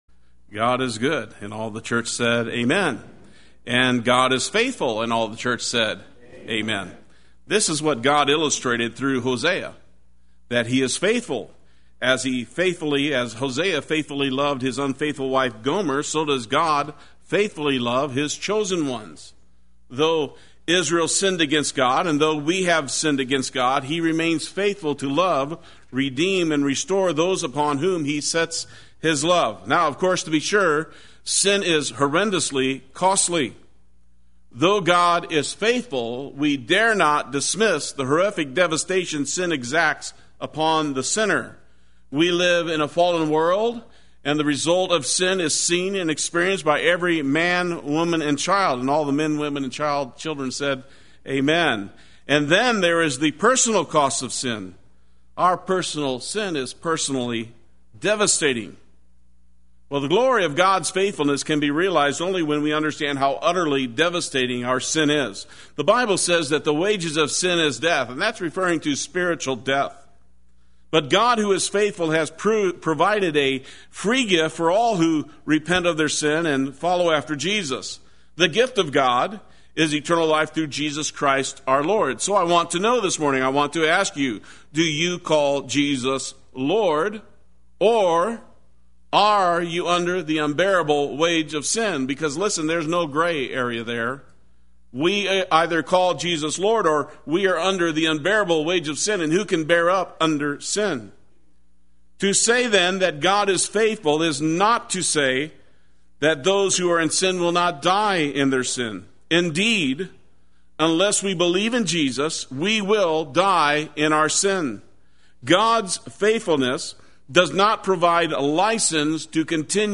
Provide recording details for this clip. It is Time to Seek the Lord Sunday Worship